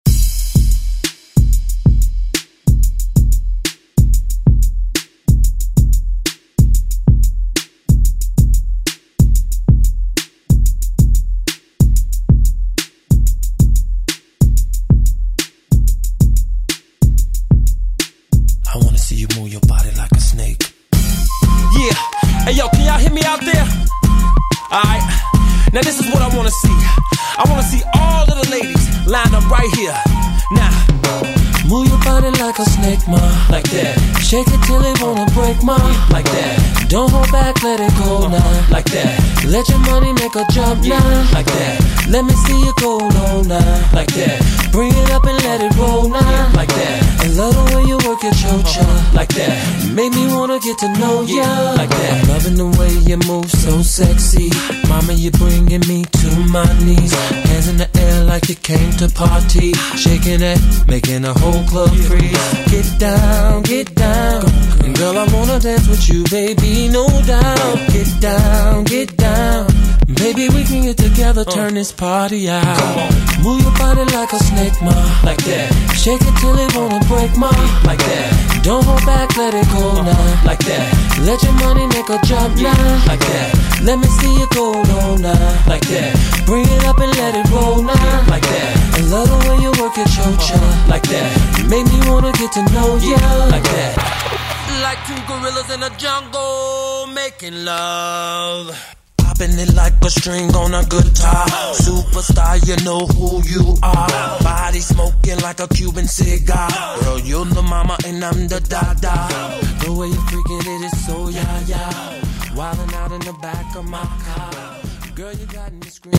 Electronic Pop Hip Hop House Music
Extended ReDrum Clean 125 bpm